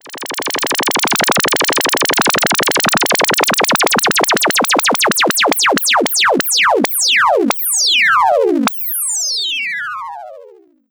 Oscillations 2.wav